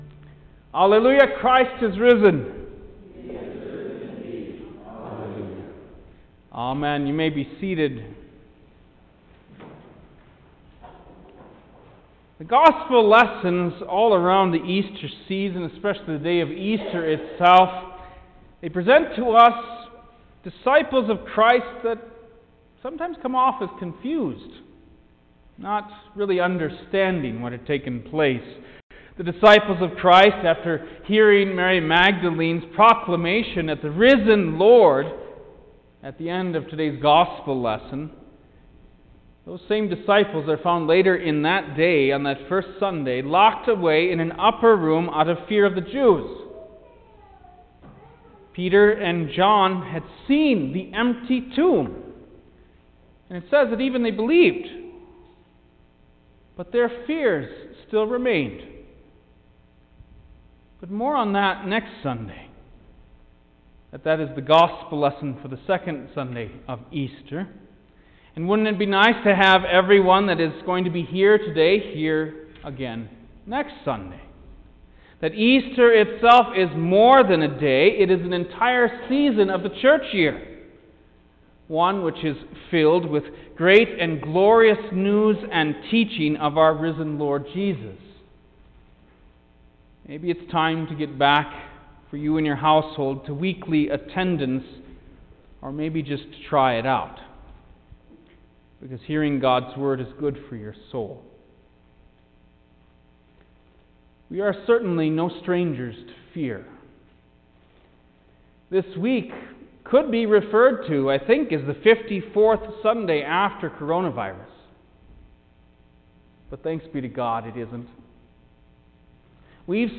April-4_2021_Easter-Sunrise-Service_Sermon-mono.mp3